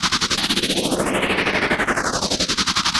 RI_RhythNoise_80-05.wav